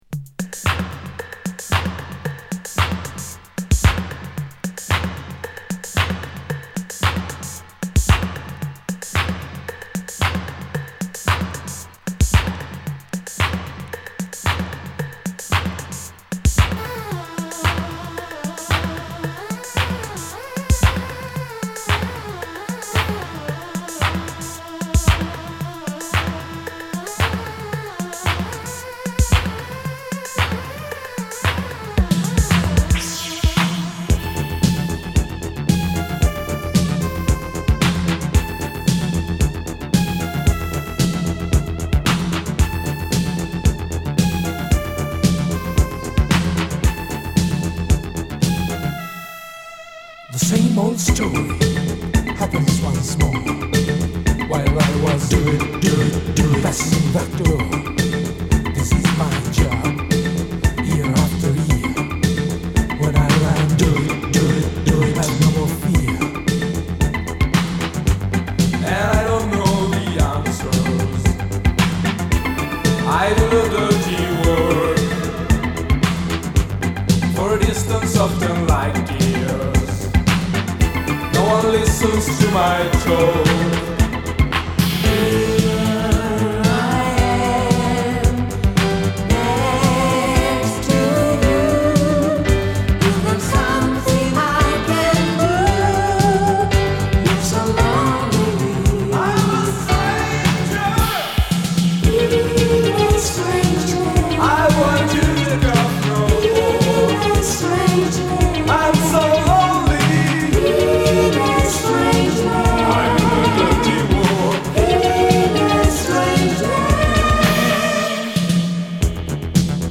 Home > Italo Disco